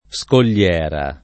scogliera [ S kol’l’ $ ra ] s. f.